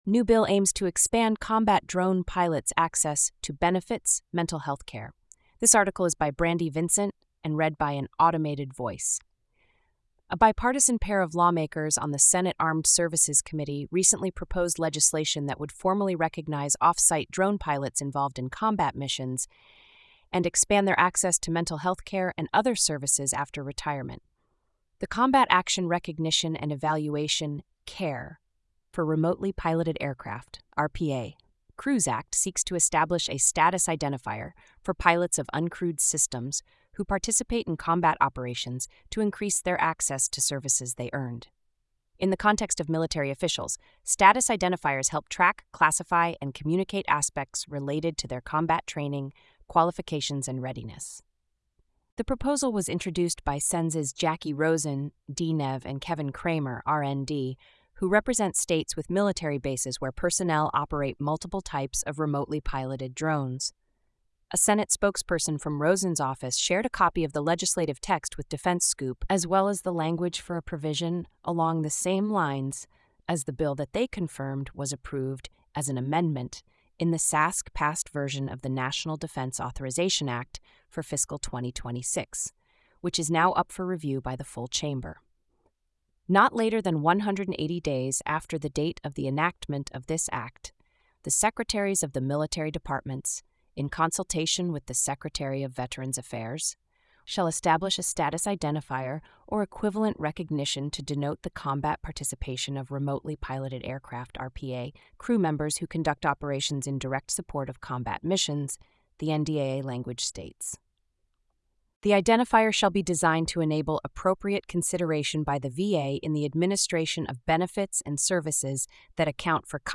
This feature uses an automated voice, which may result in occasional errors in pronunciation, tone, or sentiment.